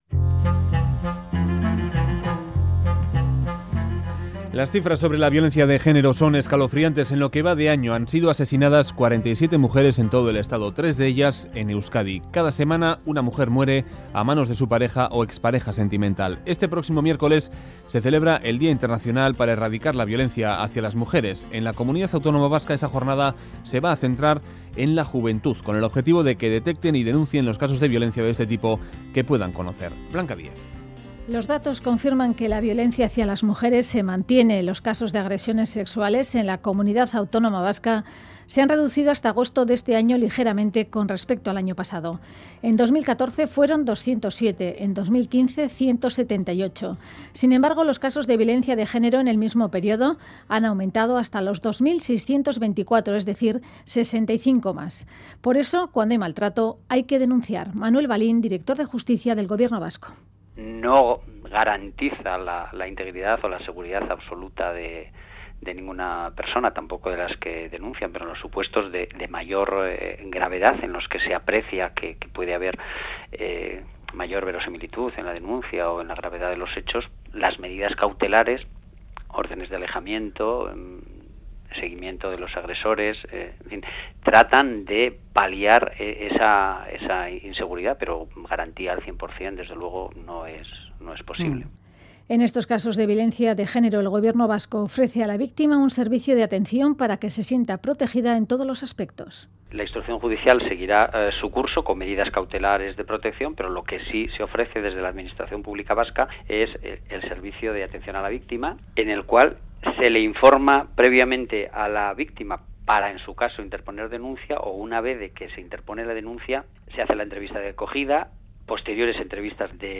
presentamos un reportaje
dos entrevistas